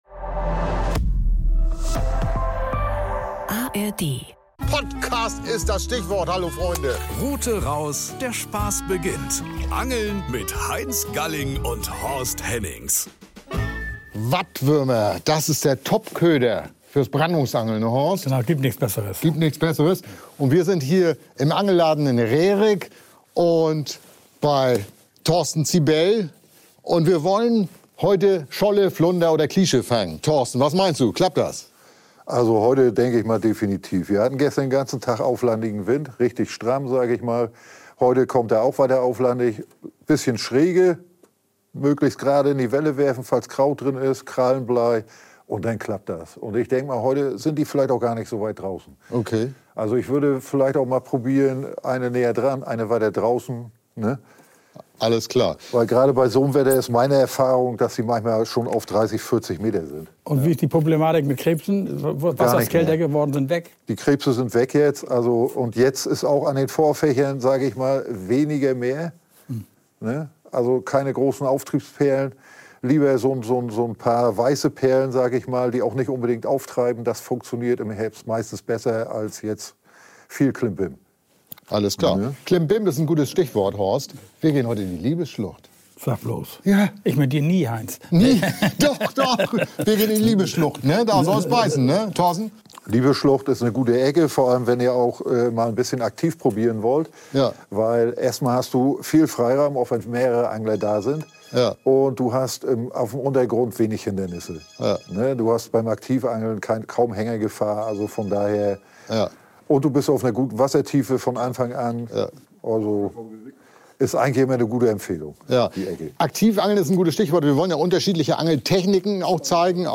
In der Nähe der „Liebes-Schlucht“ haben sie ihre langen Brandungsruten aufgebaut.